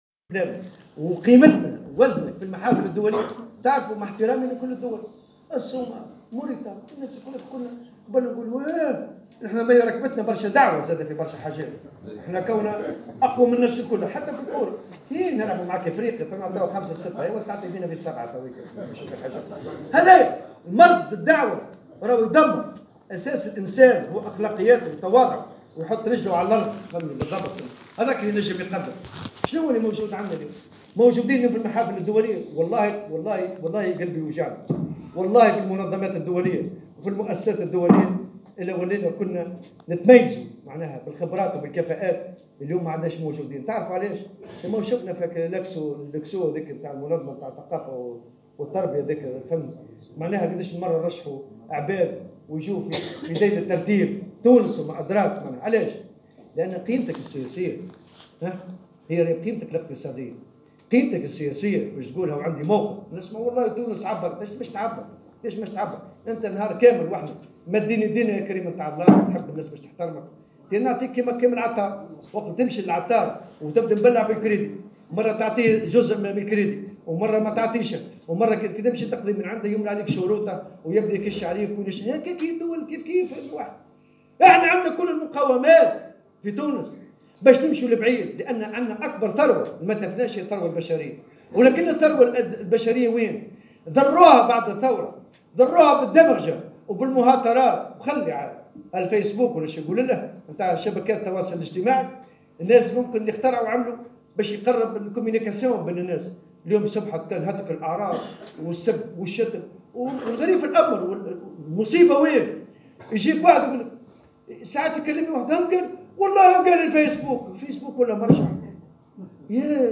واعتبر في كلمة له اليوم على هامش اختتام الندوة الوطنية للجامعة العامة للنفط والمواد الكيميائية بالحمامات، أن القيمة السياسية لأي بلاد تكون من قيمتها الإقتصادية ، مشددا على أن البلاد اليوم أمام خيار ديمقراطي يتطلب أحزاب قوية وفاعلة لرسم خيارات وطنية .